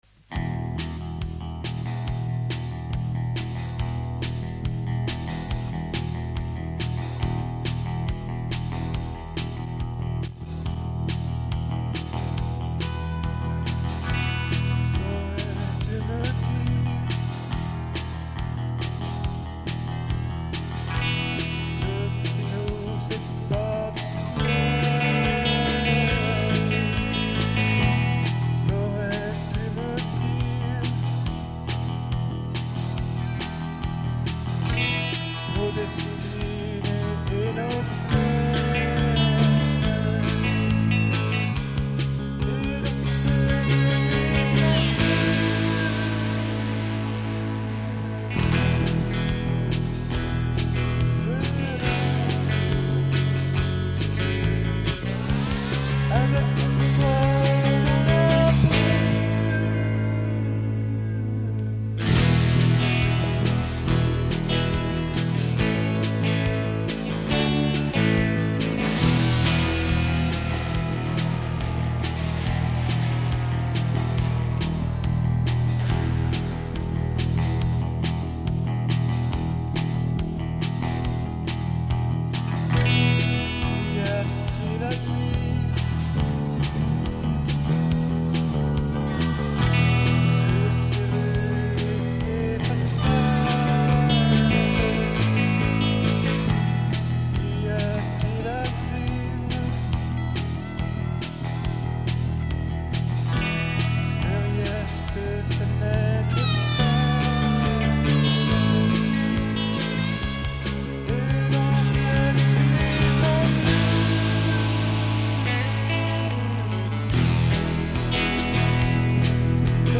Enregistré avec du matos d'il y a 10 ans, back to mono, etc.
Guitare, basse, DR550, micro, Alesis Quadraverb.
Le Portastudio sert à aligner les rythmiques, ensuite transférées prémixées sur un PC.